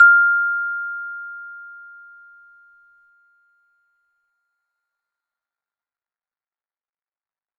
chime.wav